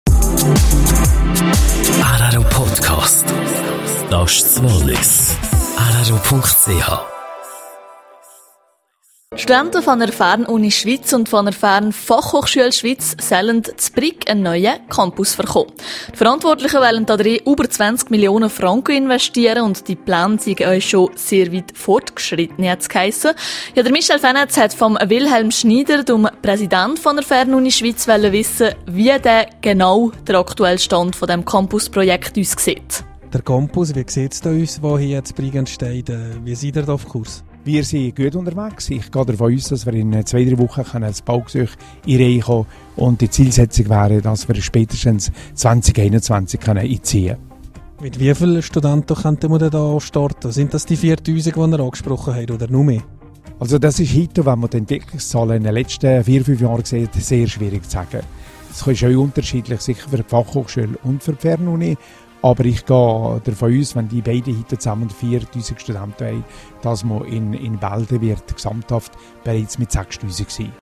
Baugesuch für Briger Bildungscampus in der Pipeline: Fernuni-Präsident Wilhelm Schnyder im Interview.